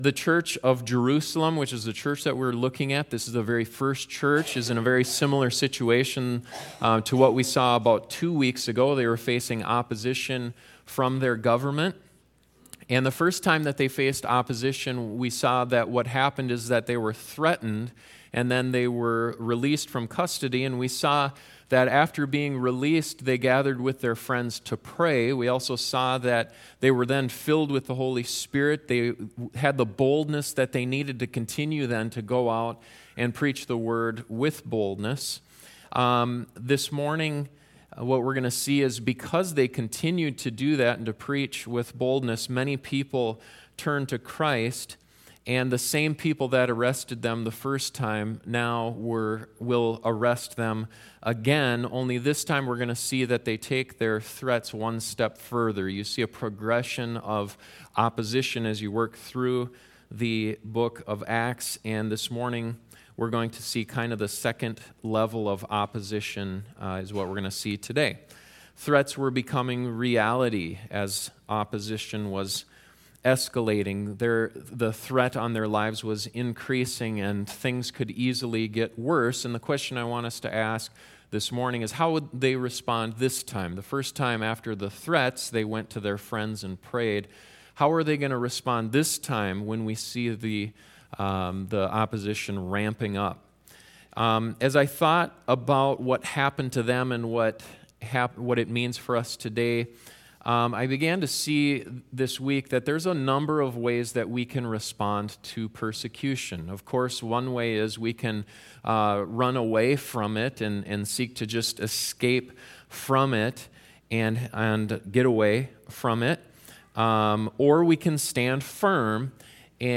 There are many ways to endure, some good some better. This sermon focuses on an excellent way to endure.